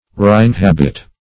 Reinhabit \Re`in*hab"it\ (-h?b"?t)